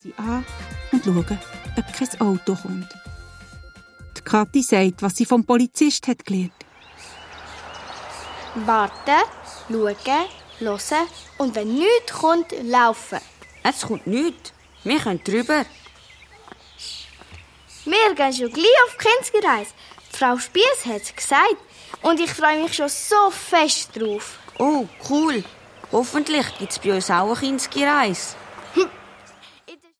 Musikalisches Hörspiel zum Schulstart